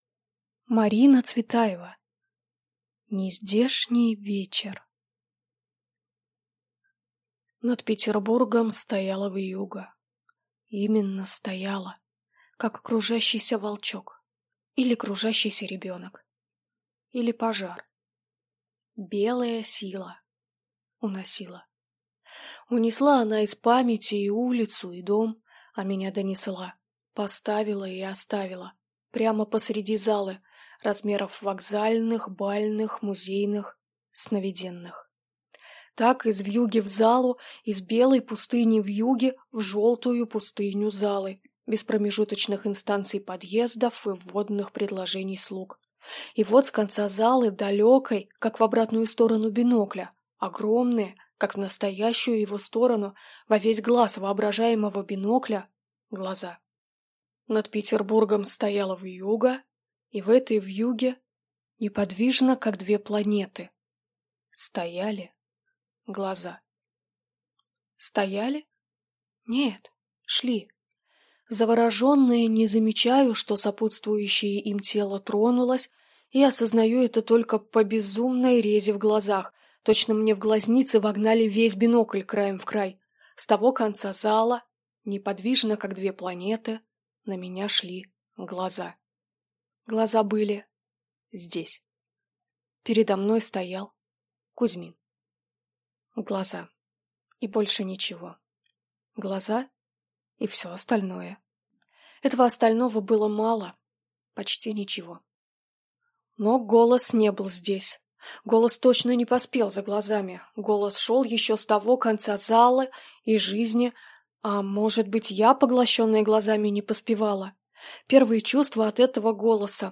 Аудиокнига Нездешний вечер | Библиотека аудиокниг